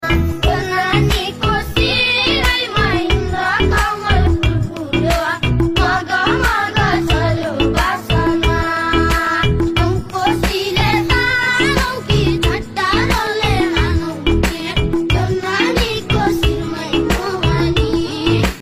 Nepali beat song